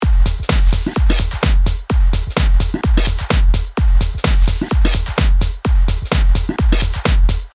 • Diode：ダイオード（名称はちょっとピンと来なかった）Tubeよりちょっとソリッドな感じ？
ドライブレベル80％：Tubeの時より少し華やかな音になっていますね。